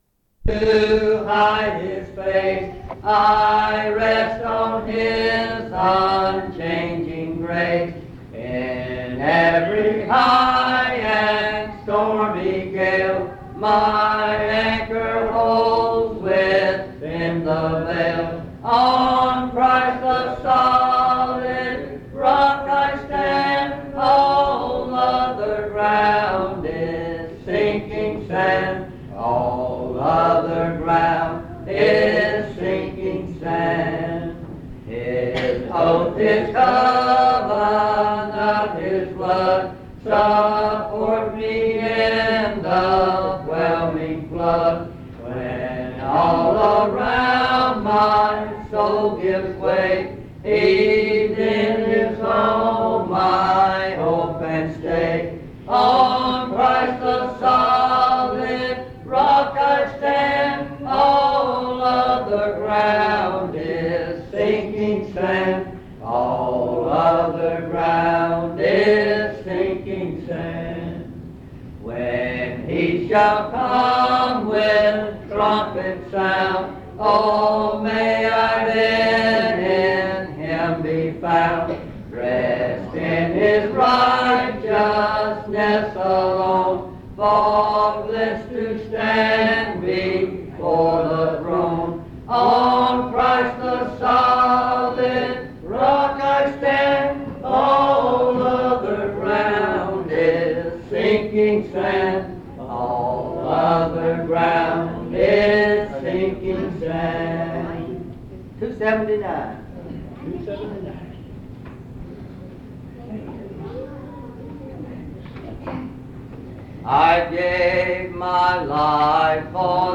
Browns Summit (N.C.)